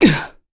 Clause-Vox_Landing_b.wav